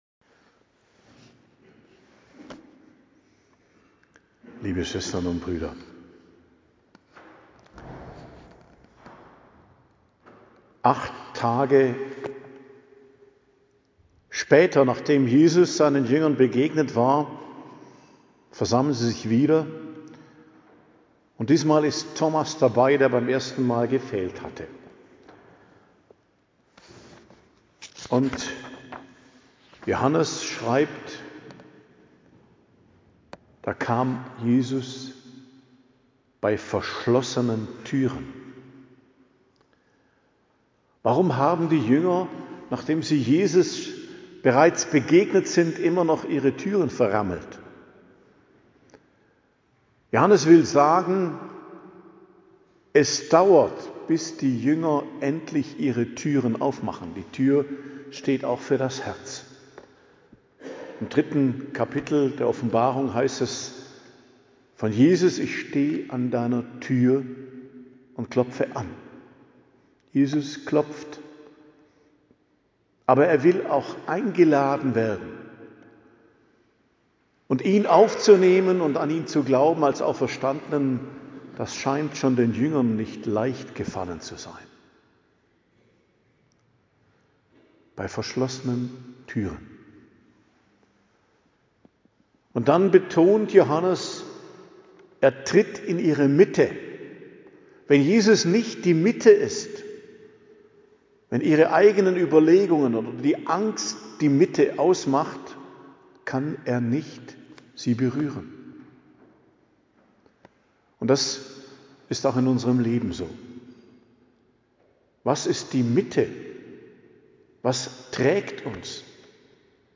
Predigt zum 2. Sonntag der Osterzeit, Weisser Sonntag, Barmherzigkeitssonntag, 12.04.2026